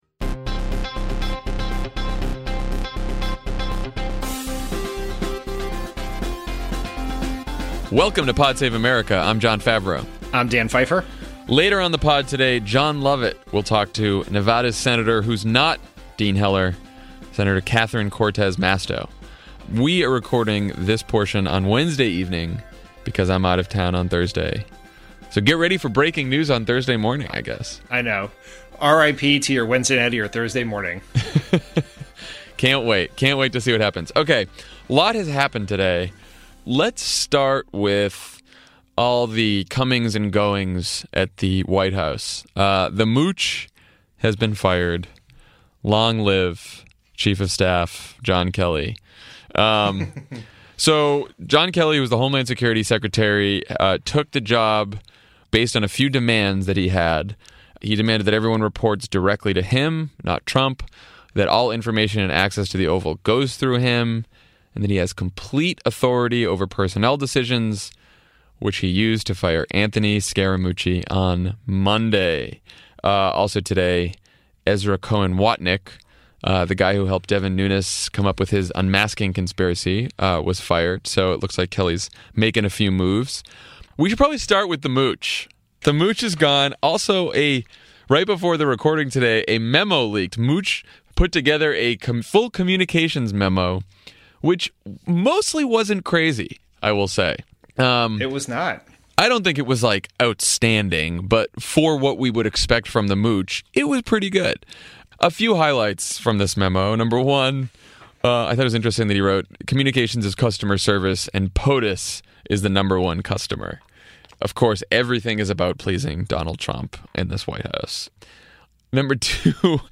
The Mooch Era is a short one, General Kelly takes control, Jeff Flake takes on Trump, and Trump and Sessions play the hits. Then Jon Lovett talks to Nevada Senator Catherine Cortez Masto about the future of the Democratic Party.